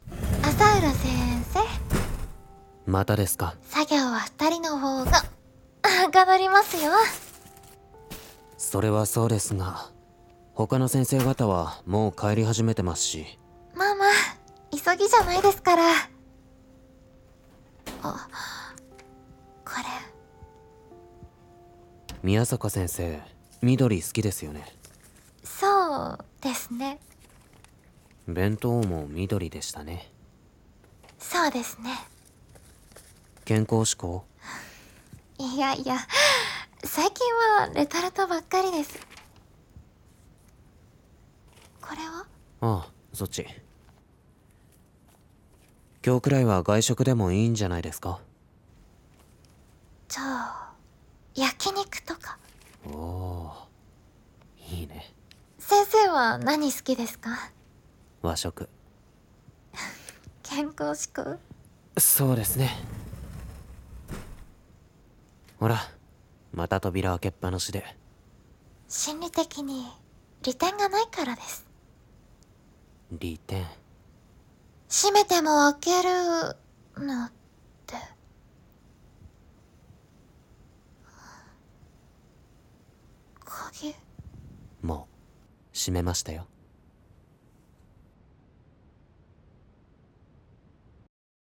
声劇】生徒禁止